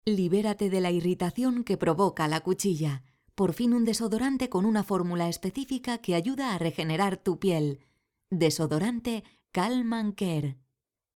Voz media. Dicción clara y precisa en castellano. Cálida, agradable, formal, profunda, divertida, seductora.
Kein Dialekt
Sprechprobe: Werbung (Muttersprache):